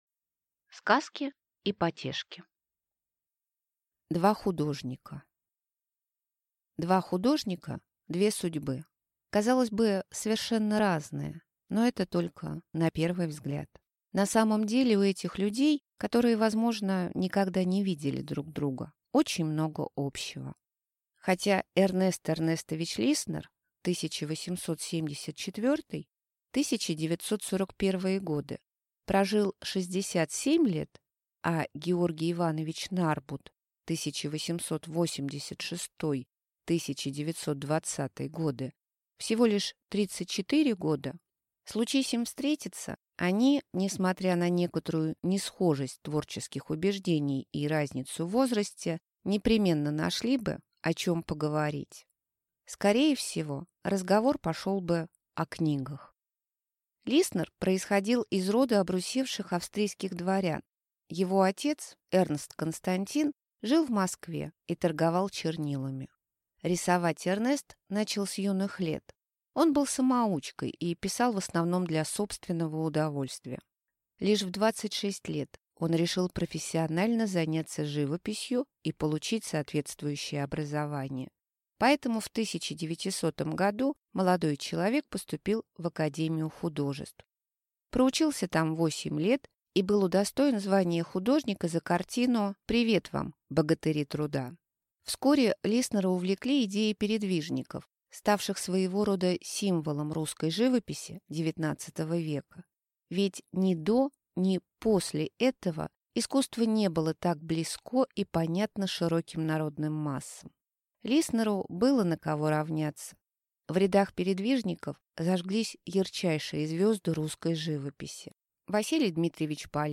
Аудиокнига Сказки и потешки | Библиотека аудиокниг